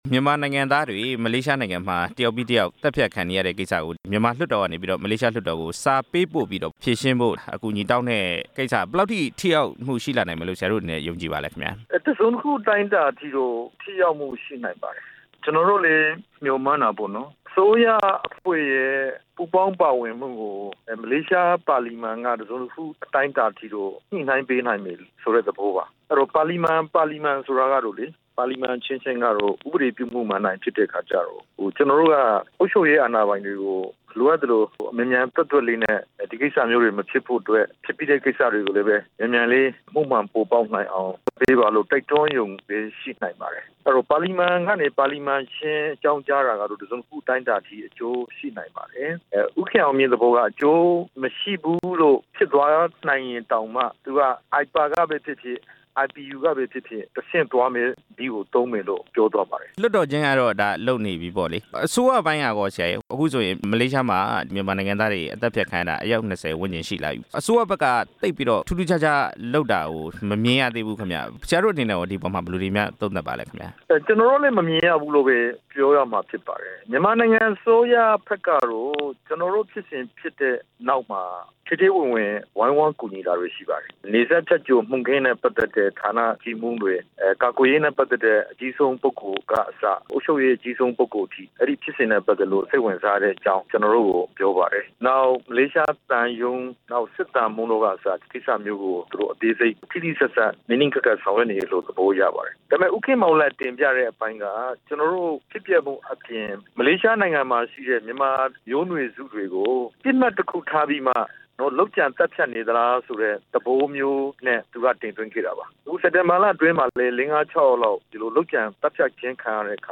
ဒေါက်တာအေးမောင်ကို မေးမြန်းချက်